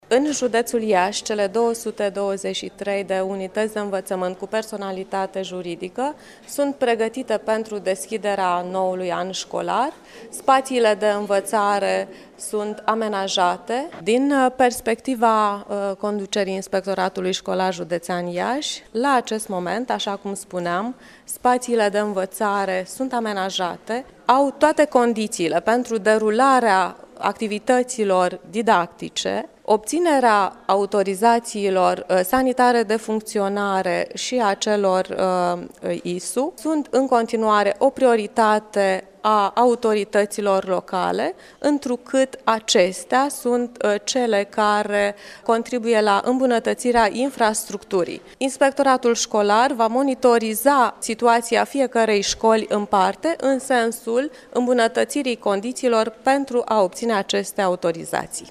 Dintre acestea, 80 la sută au autorizaţie sanitară de funcţionare – a declarat astăzi, cu prilejul începerii noului an şcolar inspectoral şcolar general Genoveva Farcaş.
Referindu-se la manualele şcolare, Genoveva Farcaş ne-a declarat că aceastea sunt asigurate în proporţie de sută la sută: